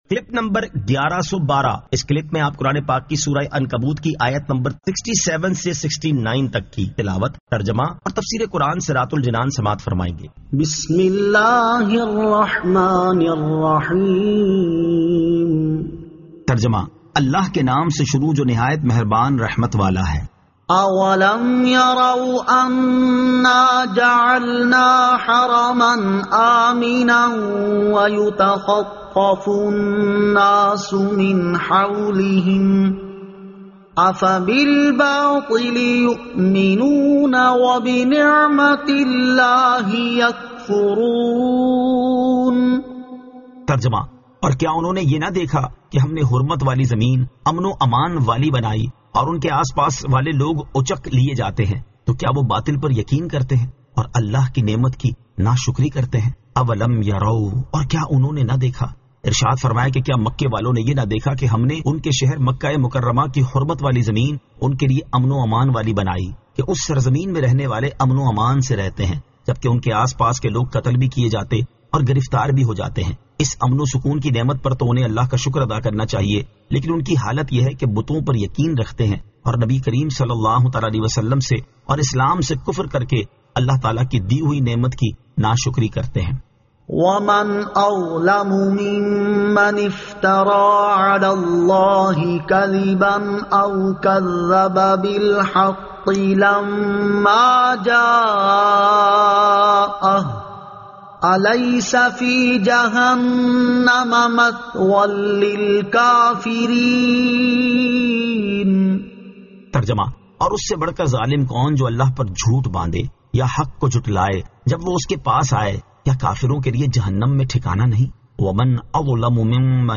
Surah Al-Ankabut 67 To 69 Tilawat , Tarjama , Tafseer